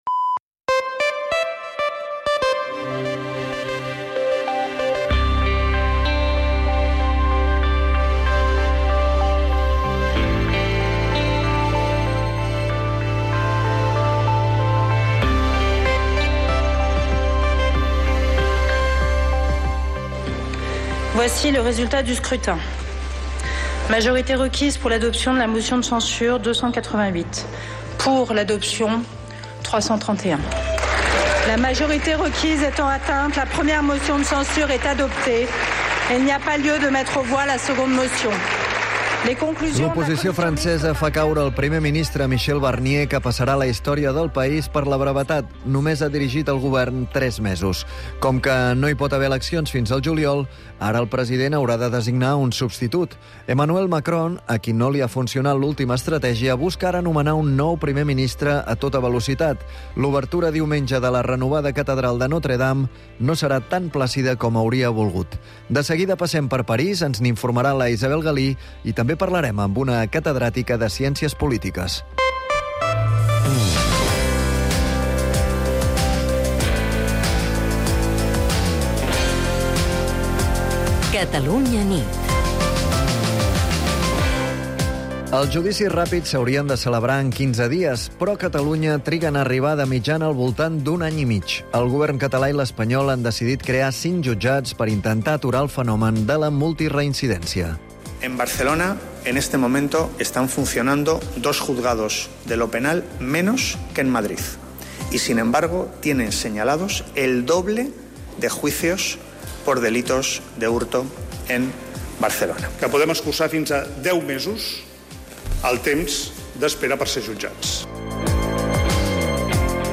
l'informatiu nocturn de Catalunya Ràdio